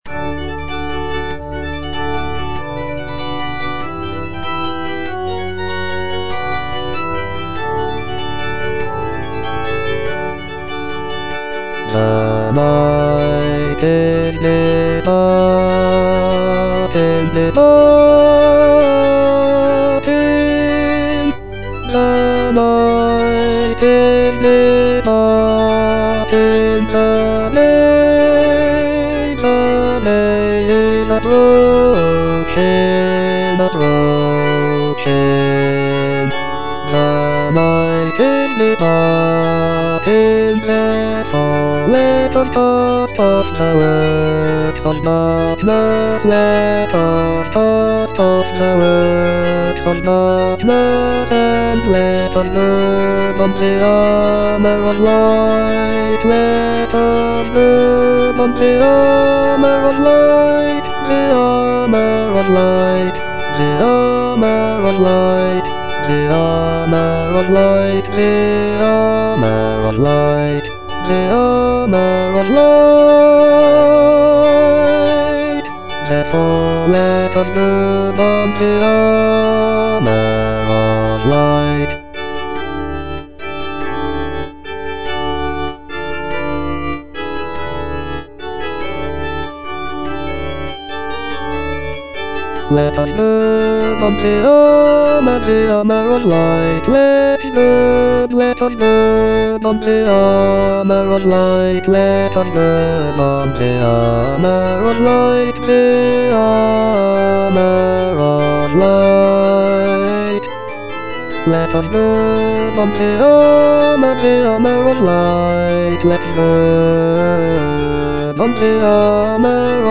F. MENDELSSOHN - HYMN OF PRAISE (ENGLISH VERSION OF "LOBGESANG") The night is departing (bass I) (Voice with metronome) Ads stop: